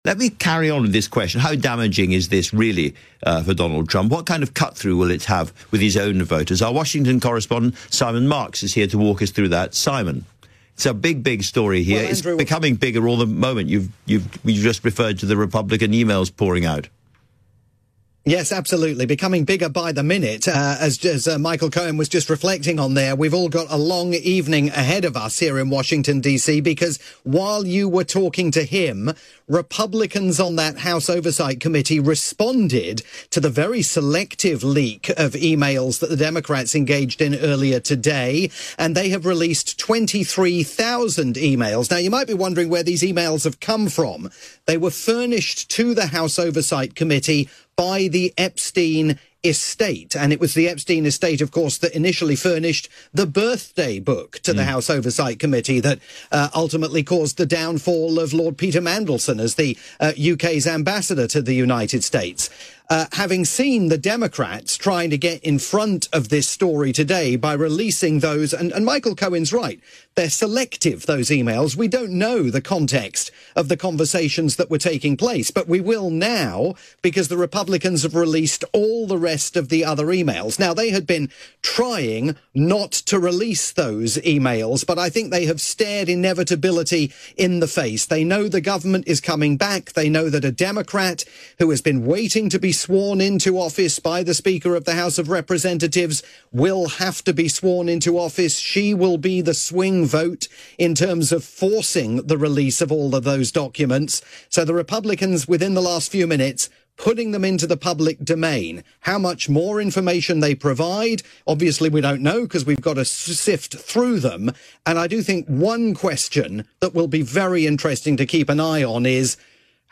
Ongoing news reports from Washington and elsewhere